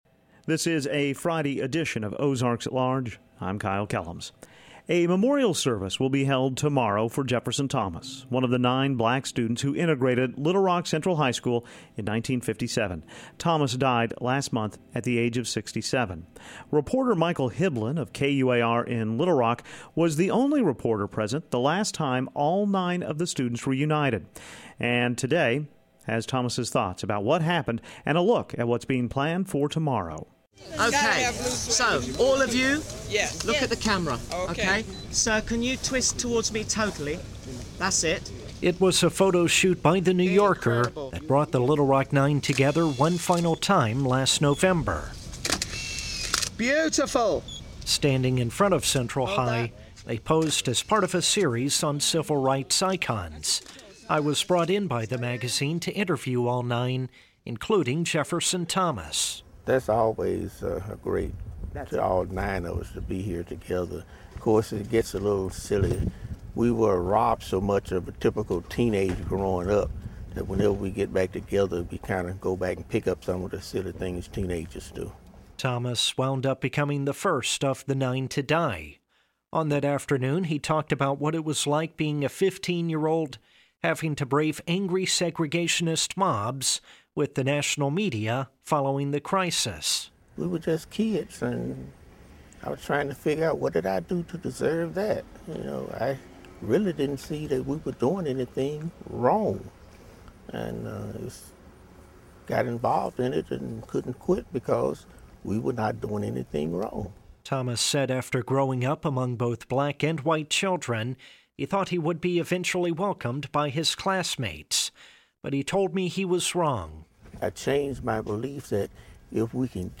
Plus the cast of "Digging Up Arkansas" performs in the Firmin-Garner Studio and talks about their musical play about the history of Arkansas. We also learn more about the Ahimsa Rescue Foundation in Muldrow and their benefit tonight in Fort Smith, get a preview of Monday's concert by the University of Arkansas Symphony Orchestra.